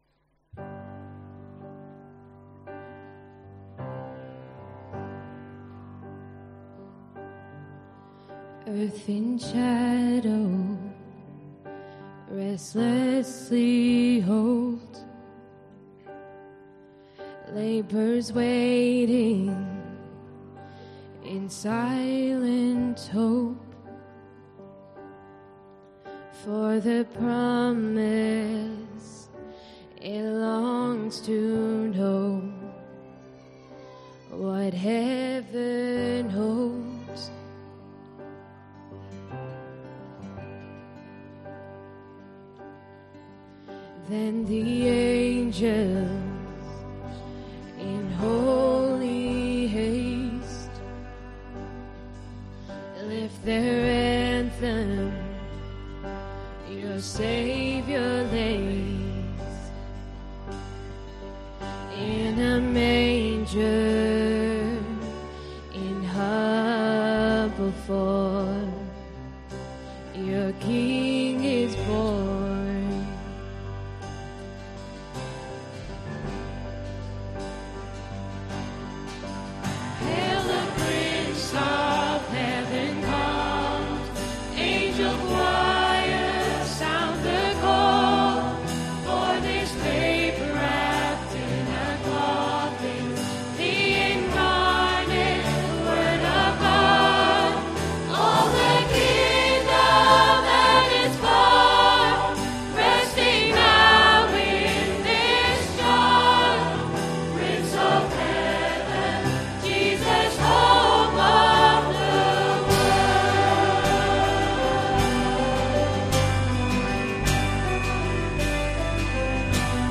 2019-12-22pm – Carol Service
Choir – Sing we the song of Emmanuel